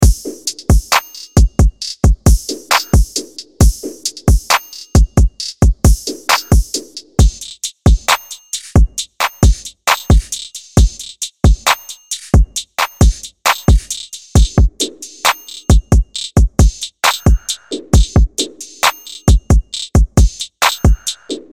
アトランタスタイルの808ベースとトラップビートが弾けるリズムサウンドを解き放つ
・力強いトラップ・ビートとチューニングされた808が、磨き上げられた荒々しさを湛える
プリセットデモ